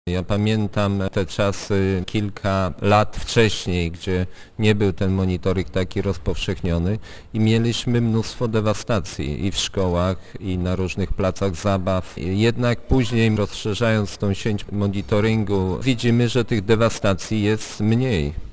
Wójt gminy Głusk Jacek Anasiewicz przyznaje, że monitoring to dość droga inwestycja, ale nie ma wątpliwości, że opłacalna: